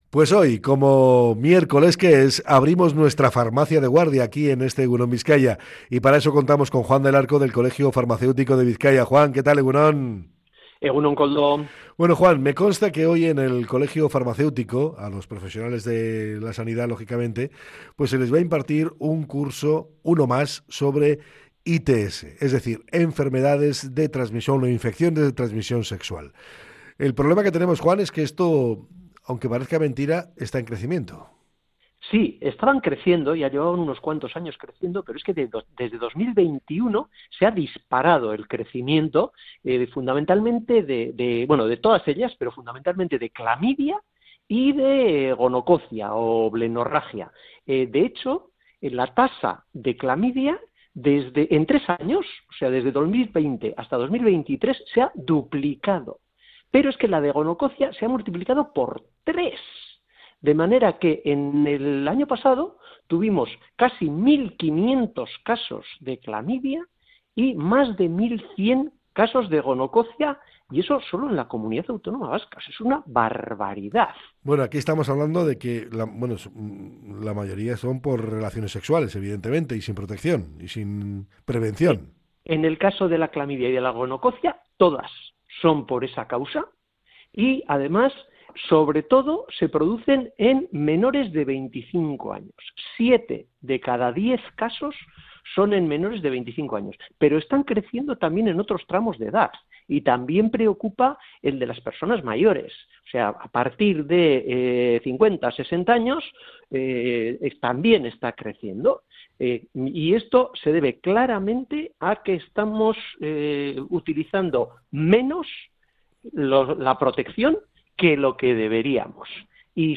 Este ha sido el tema principal en torno al que ha versado la charla